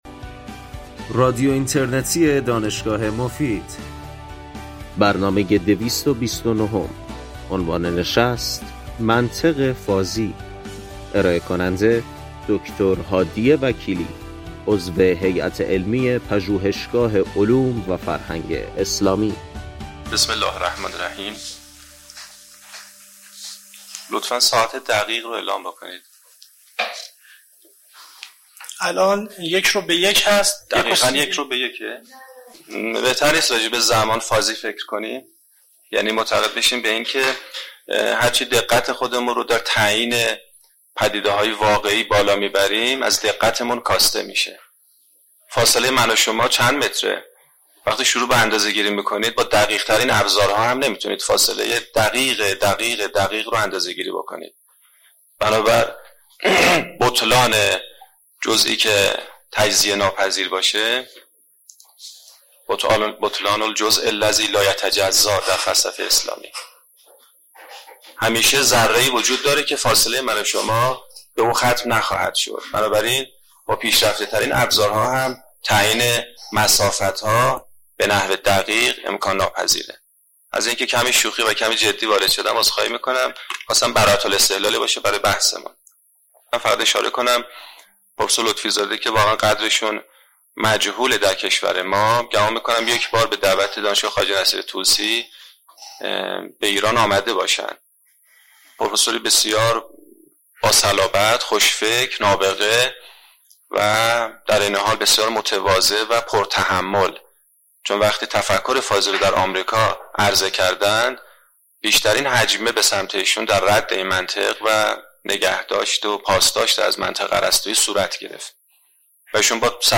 بخش پایانی برنامه به پرسش و پاسخ اختصاص دارد.